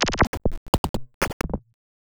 Glitch FX 04.wav